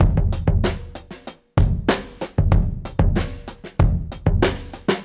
Index of /musicradar/dusty-funk-samples/Beats/95bpm
DF_BeatA_95-03.wav